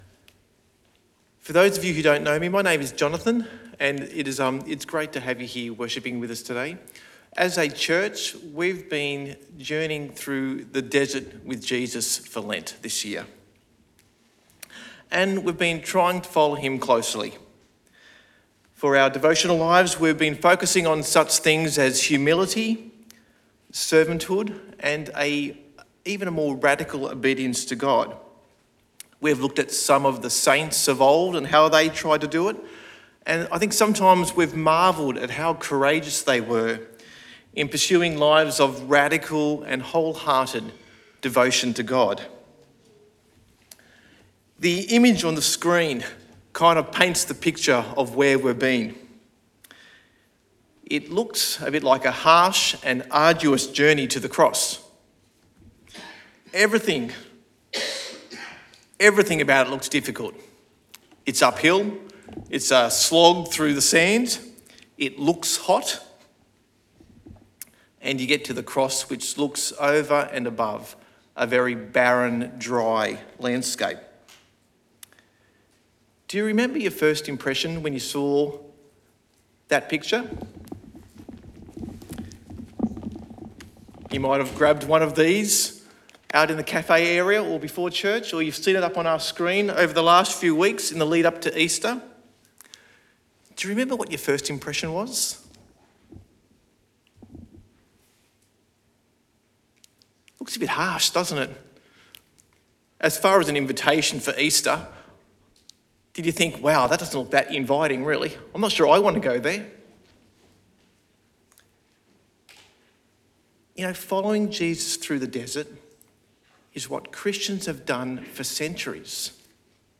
Sermon Podcasts Easter 2026